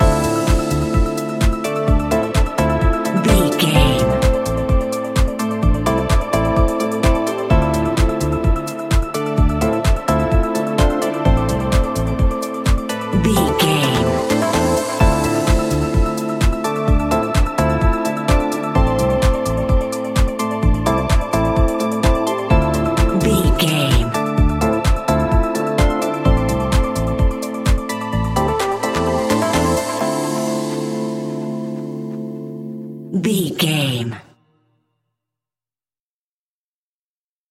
Aeolian/Minor
groovy
uplifting
energetic
drums
drum machine
synthesiser
electric piano
bass guitar
funky house
disco
upbeat